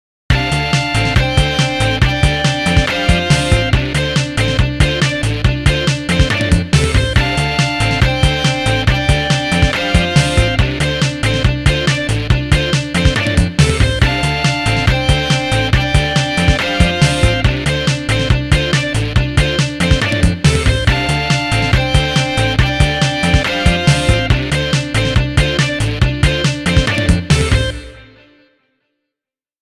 Oh lordy, what horrible guitar samples!